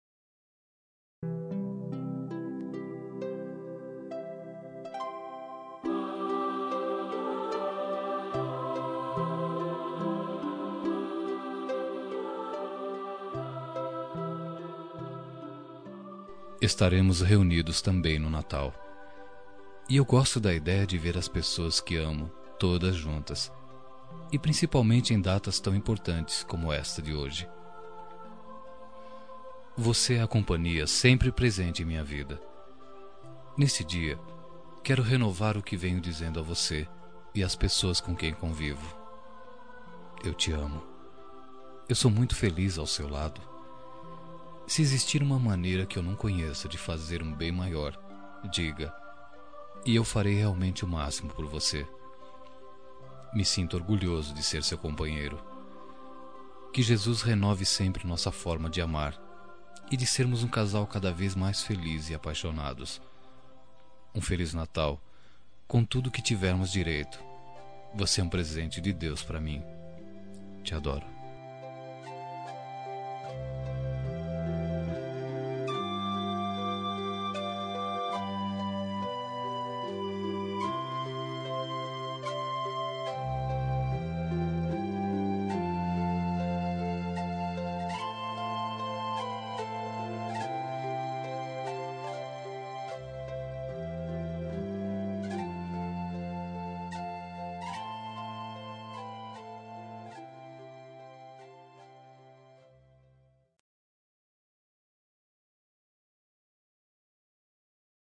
Natal Romântica – Voz Masculina – Cód: 34804